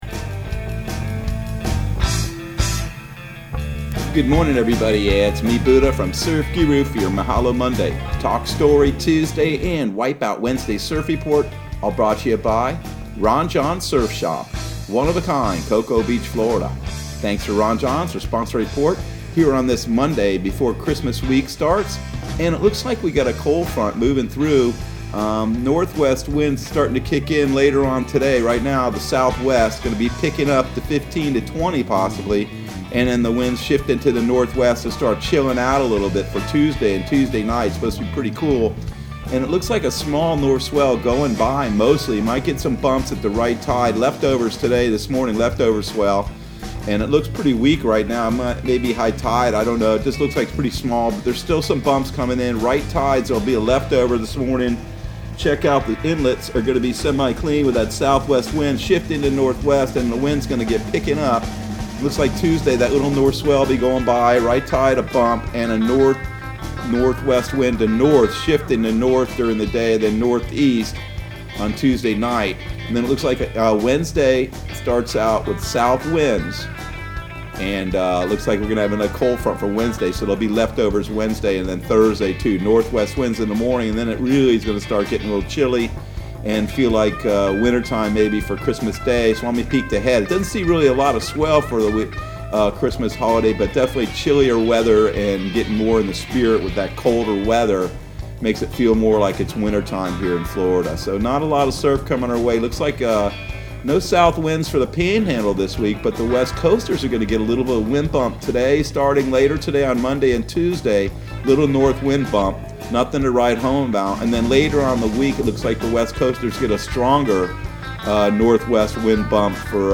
Surf Guru Surf Report and Forecast 12/21/2020 Audio surf report and surf forecast on December 21 for Central Florida and the Southeast.